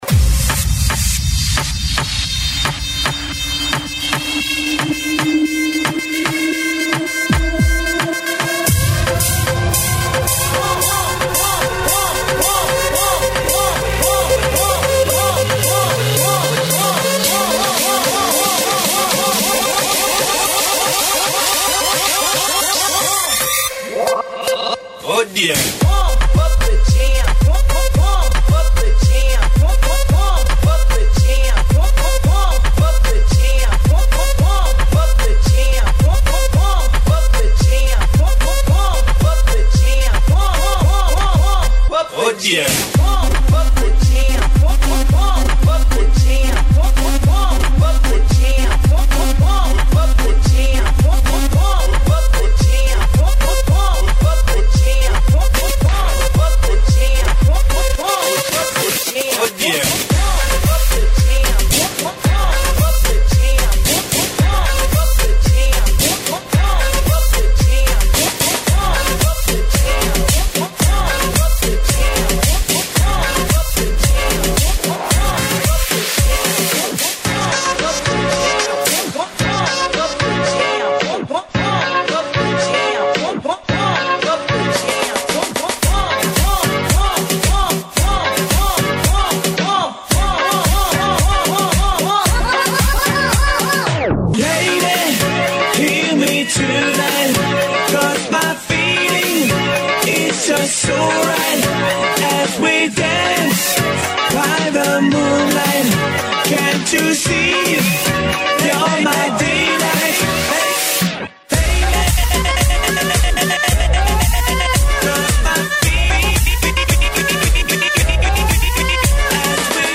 Género: Blues.